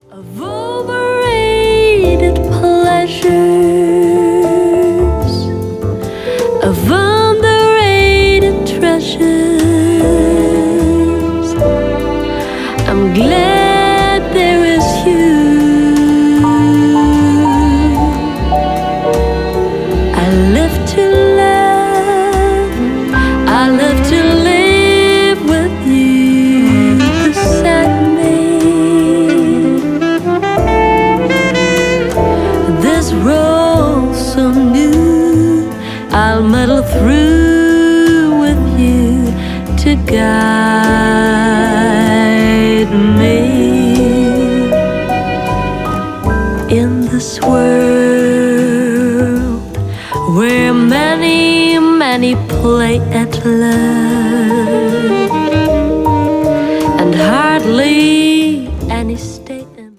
钢琴
贝斯
鼓
萨克斯
次中音萨克斯
弦乐四重奏